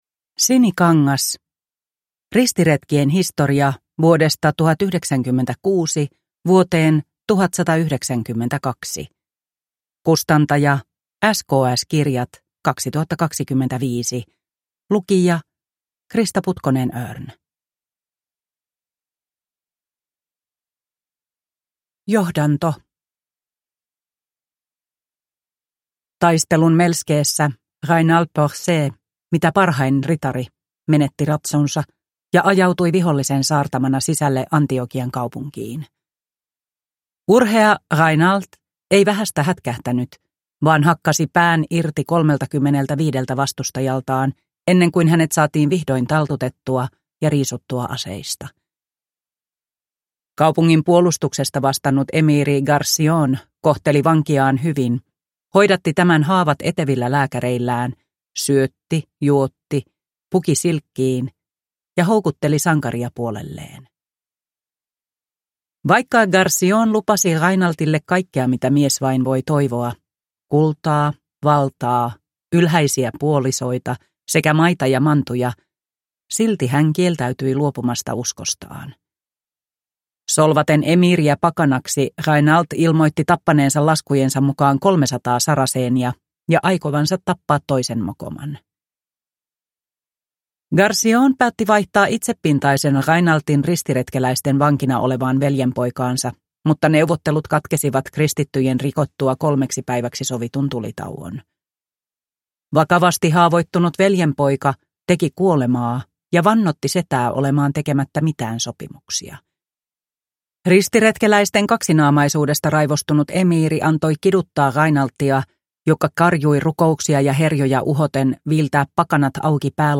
Ristiretkien historia – Ljudbok